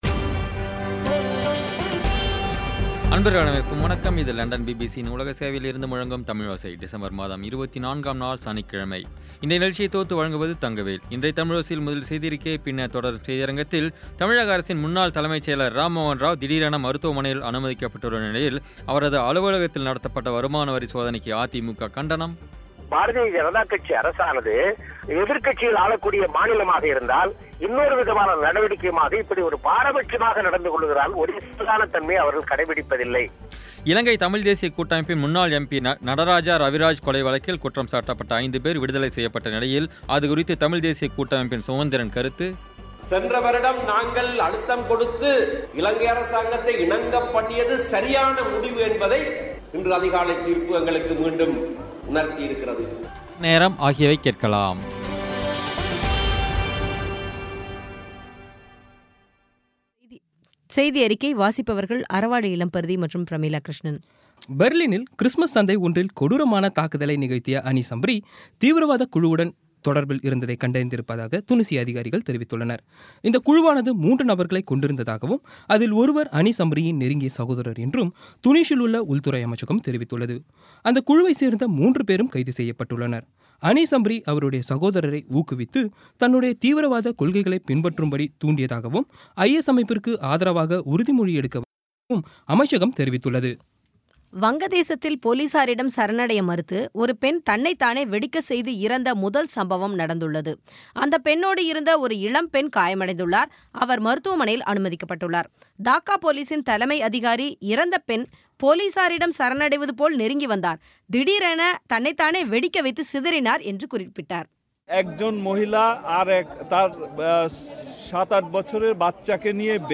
இன்றைய தமிழோசையில், முதலில் செய்தியறிக்கை, பின்னர் தொடரும் செய்தியரங்கத்தில், தமிழக அரசின் முன்னாள் தலைமைச் செயலர் ராம்மோகன ராவ் திடீரென மருத்துவமனையில் அனுமதிக்கப்பட்டுள்ள நிலையில், அவரது அலுவலகத்தில் நடத்தப்பட்ட வருமான வரி சோதனைக்கு அதிமு கண்டனம் இலங்கை தமிழ் தேசிய கூட்டமைப்பின் முன்னாள் எம்.பி. நடராஜா ரவிராஜ் கொலை வழக்கில் குற்றம் சாட்டப்பட்ட ஐந்து பேர் விடுதலை செய்யப்பட்ட நிலையில், அதுகுறித்து தமிழ் தேசியக் கூட்டமைப்பின் சுமந்திரன் கருத்து மற்றும் நேயர் நேரம் ஆகியவை கேட்கலாம்.